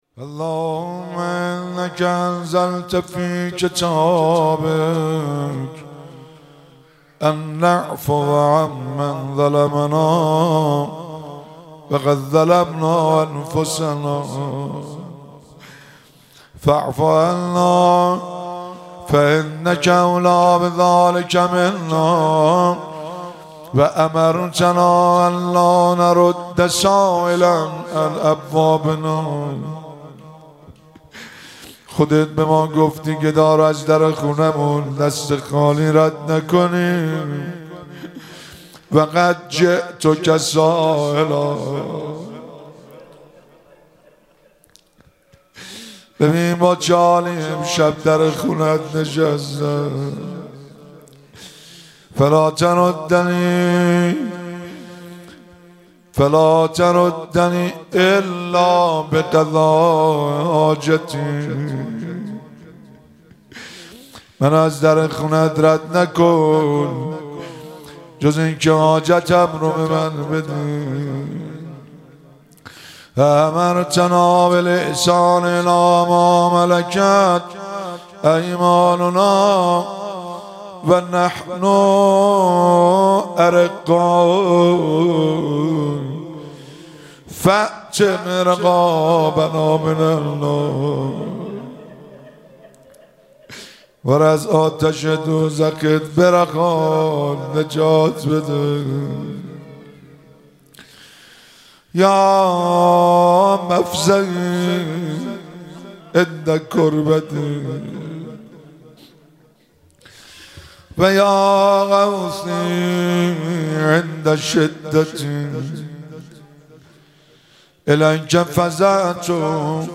مراسم مناجات خوانی شب هجدهم ماه رمضان 1444